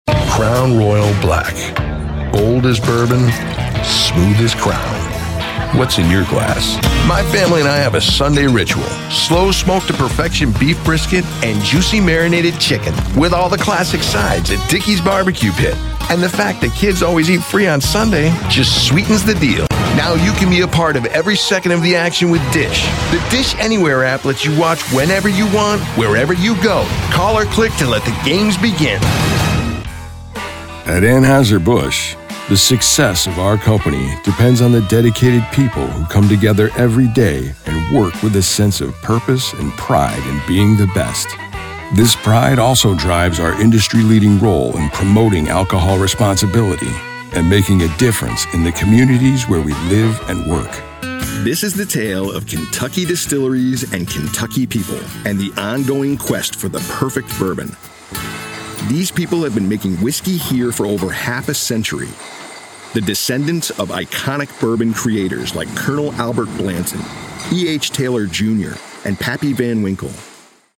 SOURCE-CONNECT Certified US MALE VOICOVER with HOME STUDIO
• BOOTH: Whisper Room, acoustically-treated
cool
MIX3-cool.mp3